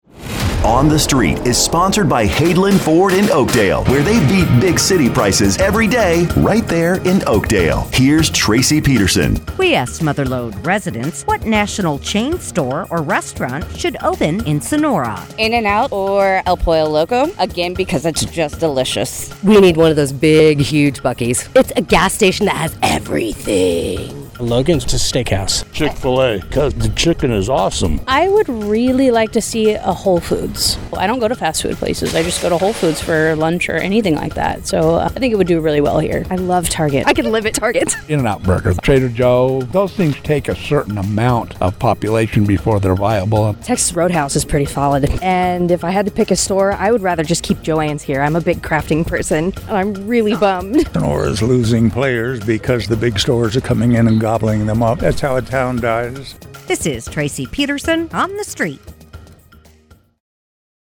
asks Mother Lode residents, “What national chain store or restaurant should open in Sonora?”